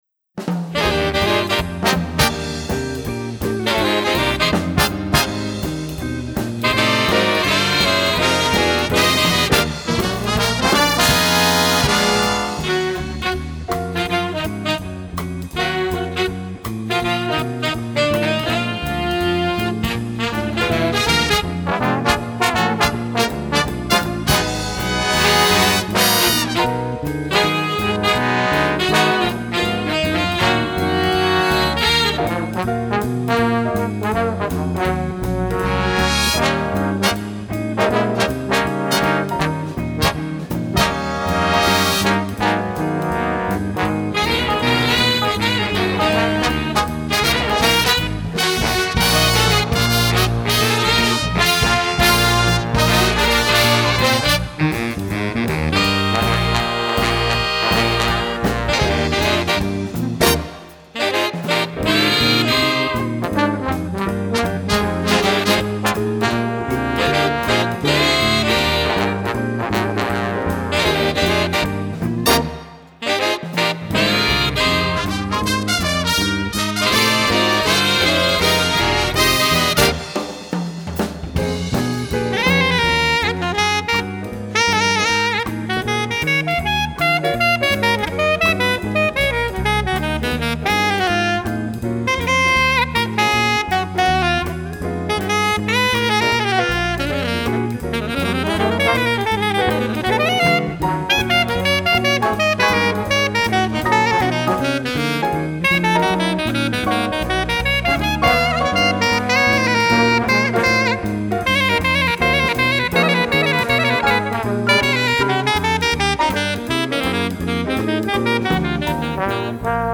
Alto Sax
trombone.